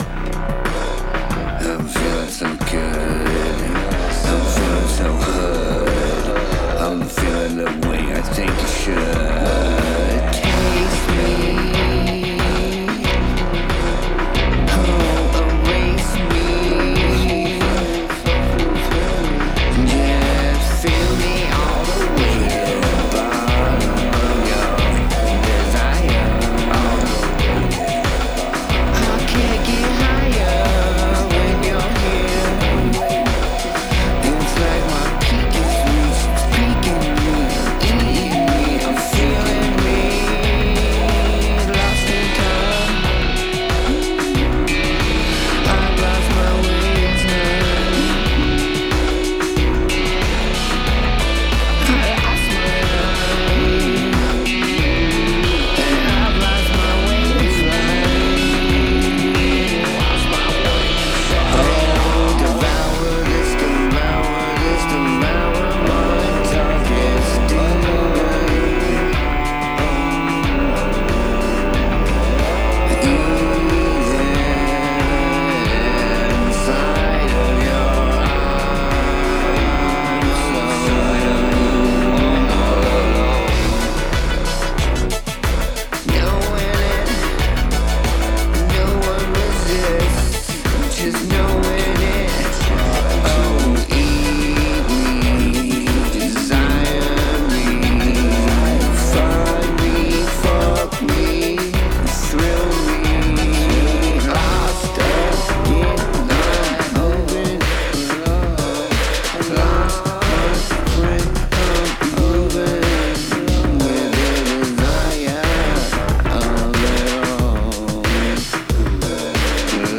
Date: 2026-01-07 · Mood: dark · Tempo: 92 BPM · Key: C major